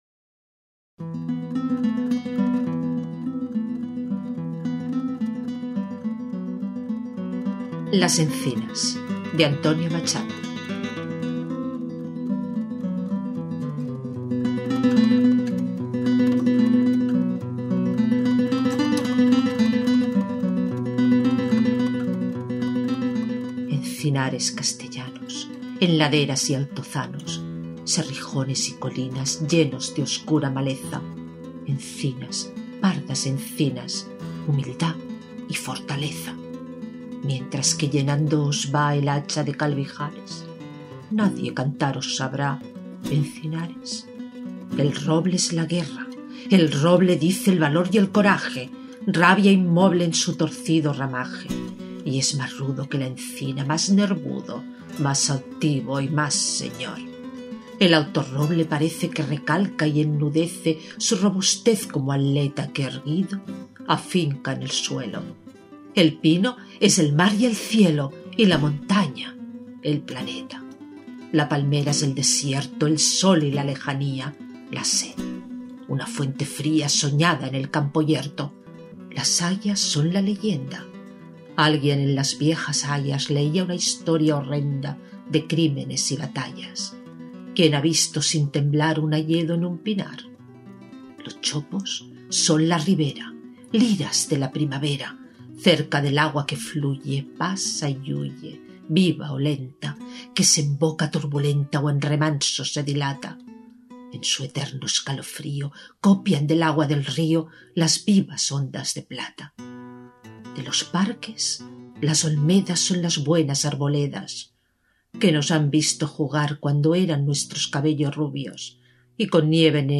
Poema
Música: Musopen (cc:by-nc-sa)